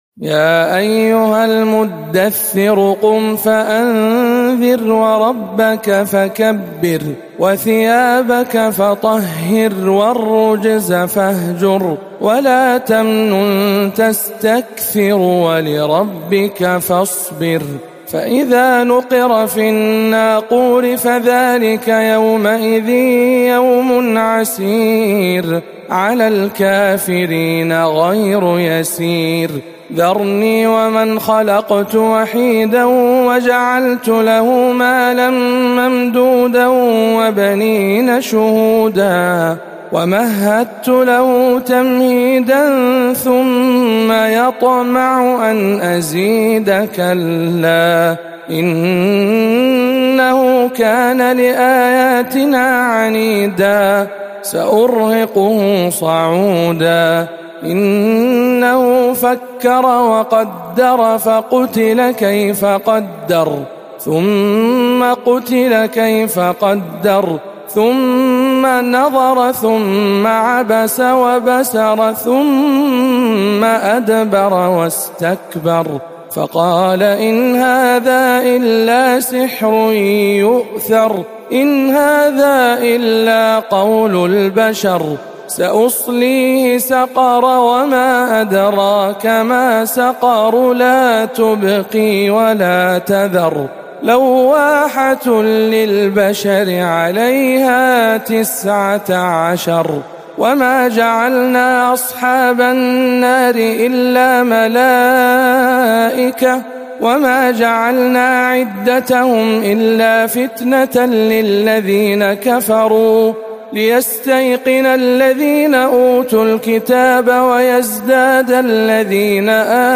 سورة المدثر بجامع أم الخير بجدة - رمضان 1439 هـ